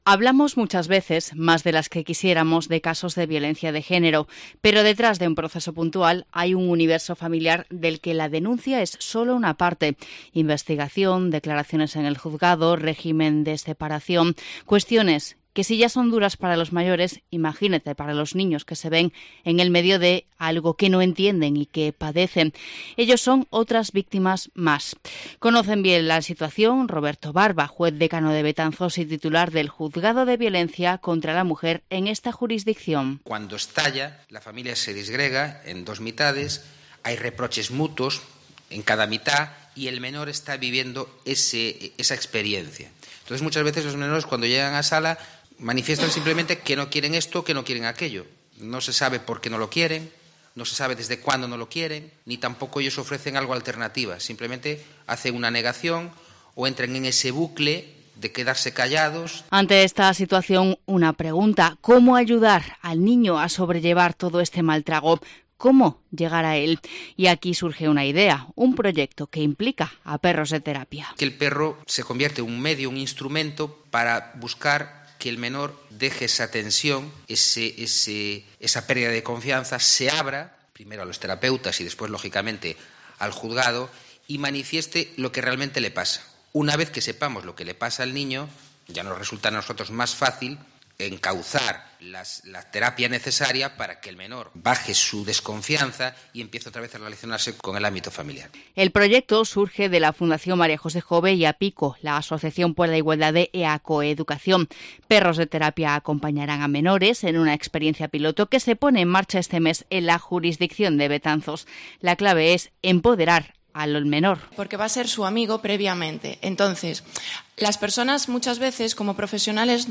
Reportaje